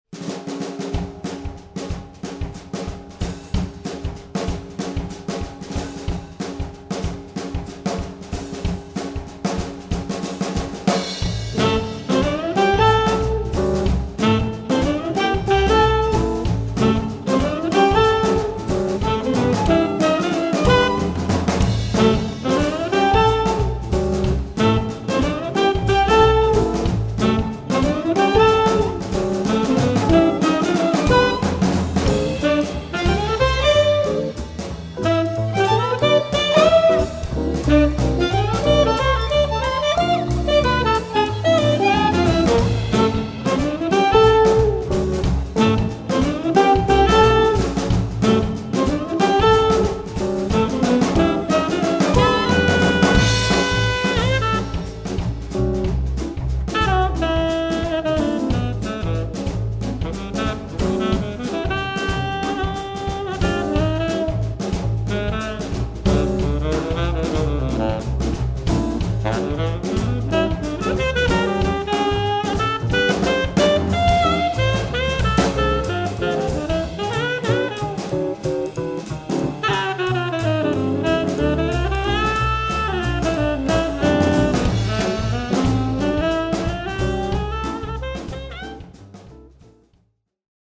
tenorski saksofon
violina
kitara
klavir, rhodes
kontrabas
bobni